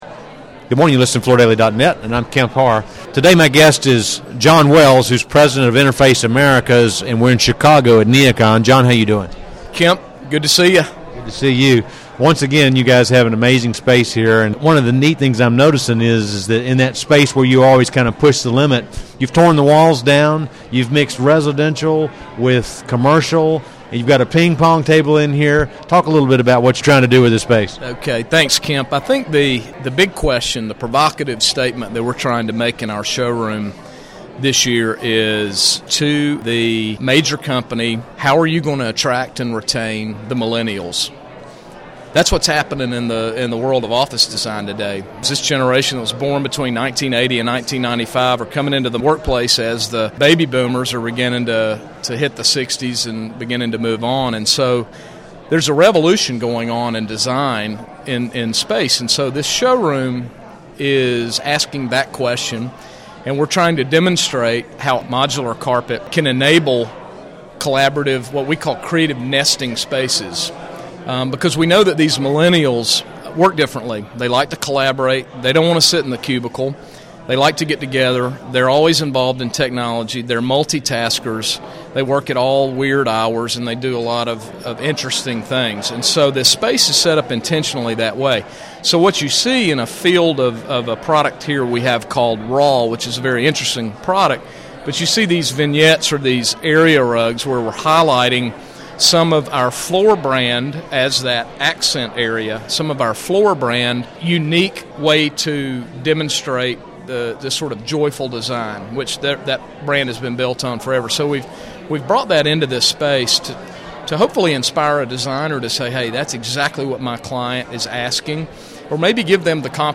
Listen to the interview to hear about the offices of the future and how Interface's sustainability initiatives have evolved.